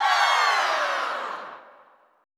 Index of /90_sSampleCDs/Best Service - Extended Classical Choir/Partition I/AHH FALLS
AHH HI SLW-L.wav